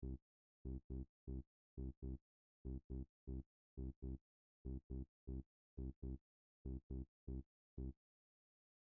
All one needs to do is invert the phase of either the left or right channel – and done! On loud speakers now, the sound will appear to come from somewhere outside the speakers and envelope the listener.